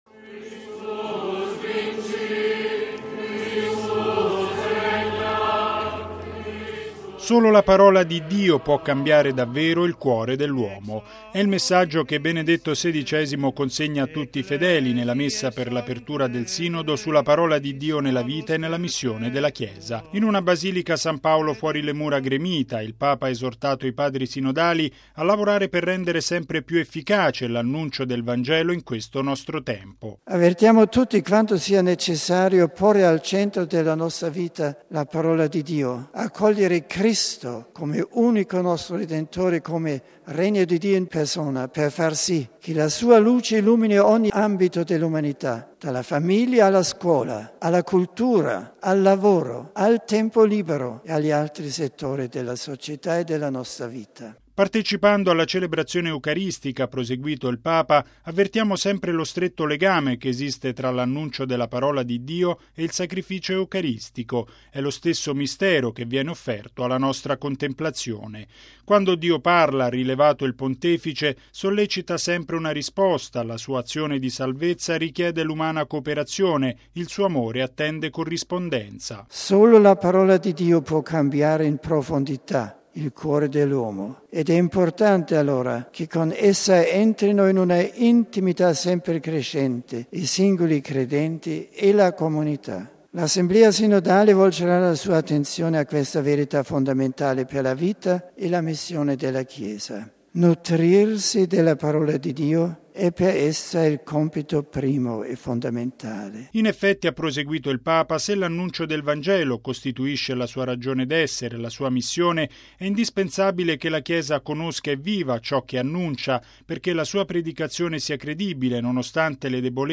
(Canti: Laudes Regiae)
(Canti: Ave Regina Caelorum)